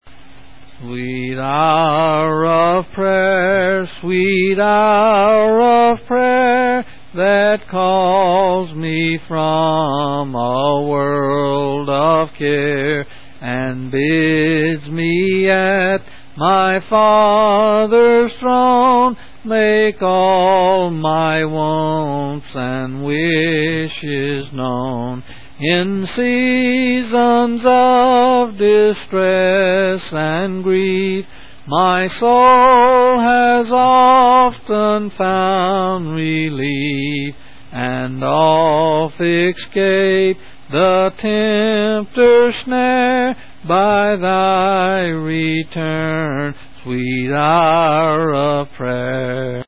8's